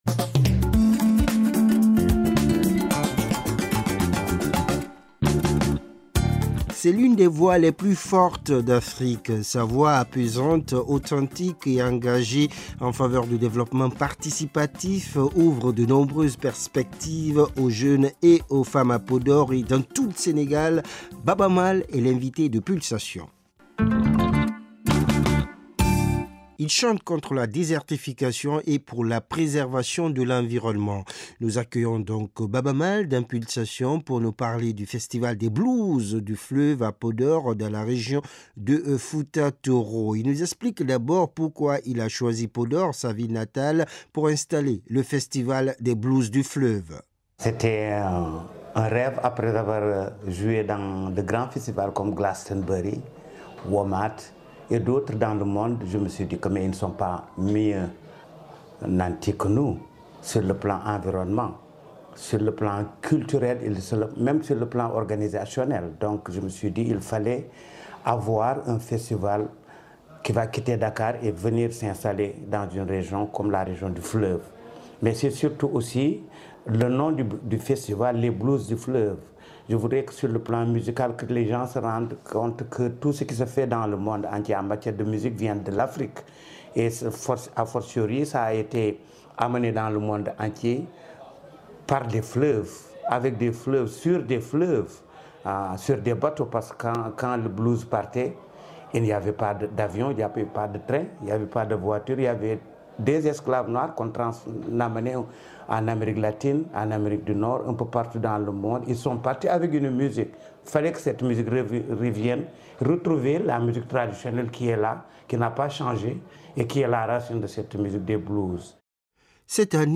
Notre invité, Baaba Maal, nous parle du festival des "Blues …